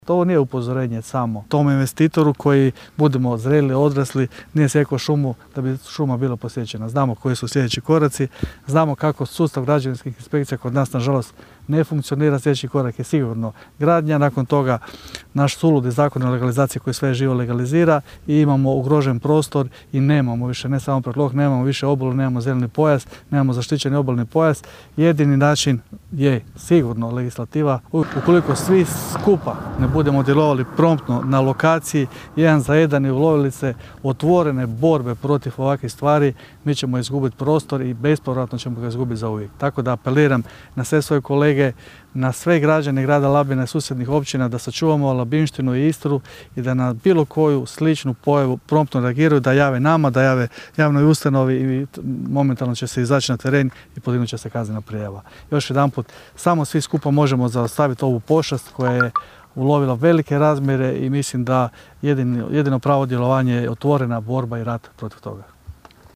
ton – Valter Glavičić 4), zaključio je gradonačelnik Labina Valter Glavičić.